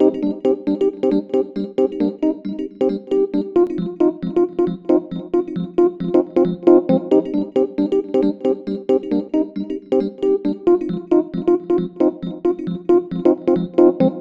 electricpiano_modulated_dance_135_bpm_Cm_25T.wav